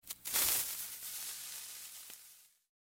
Creeper Hiss Effect